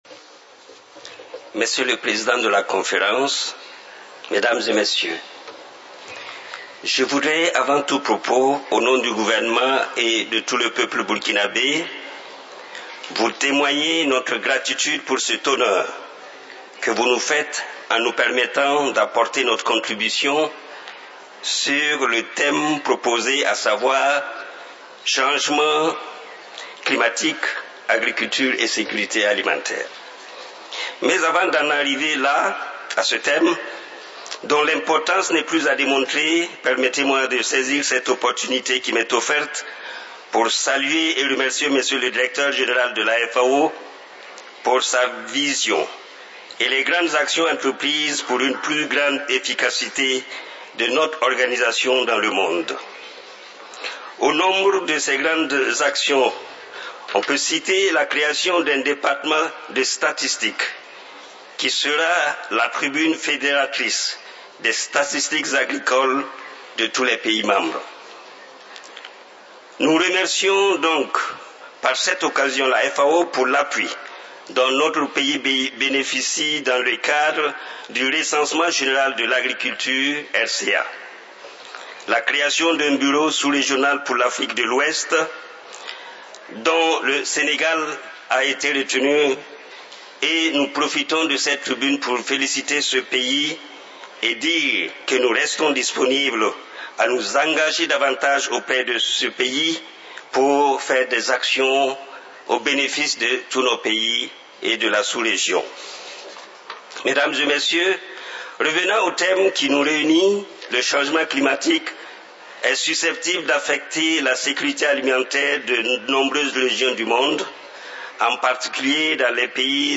FAO Conference
Statements by Heads of Delegations under Item 9:
Son Excellence Monsieur Jacob Ouédraogo, Ministre de l'agriculture et des aménagements hydrauliques du Burkina Faso